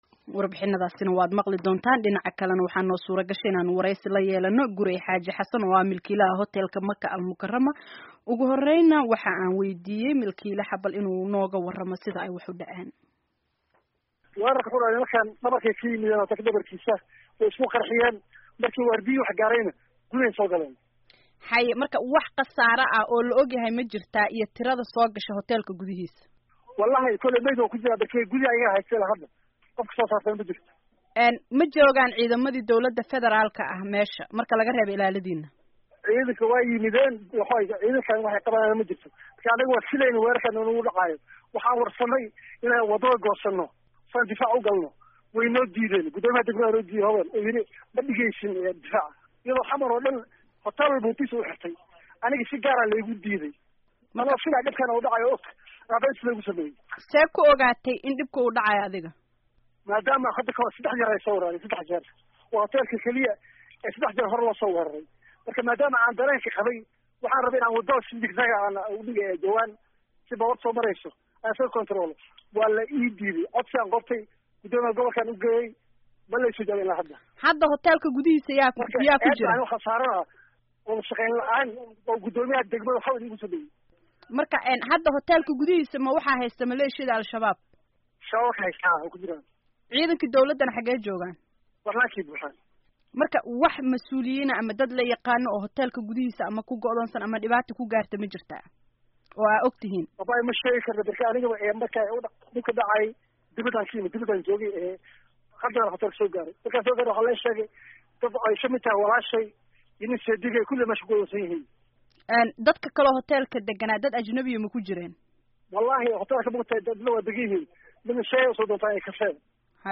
Waraysiyada kala duwan ee Weerarkii Muqdisho